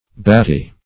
Batty \Bat"ty\ (b[a^]t"t[y^]), a.